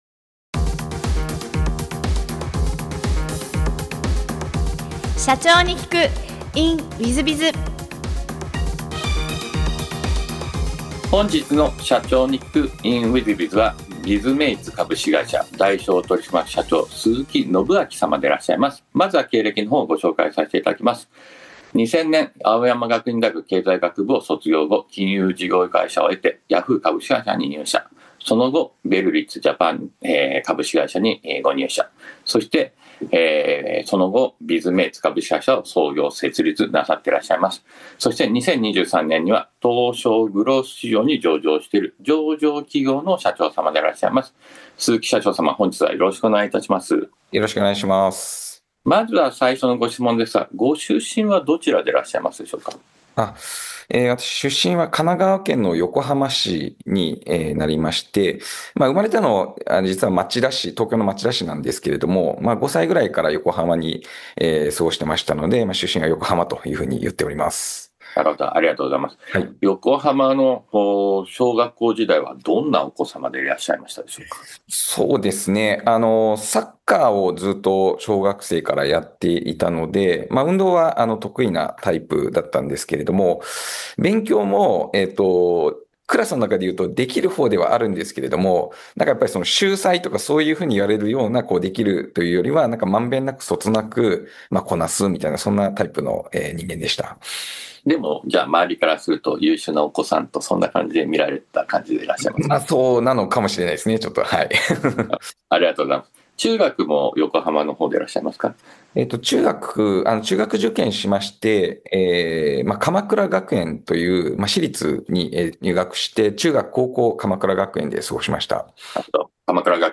業績を伸ばし、同社を上場へと導いたエピソードから経営のヒントが得られます。ぜひ、インタビューをお聞きください。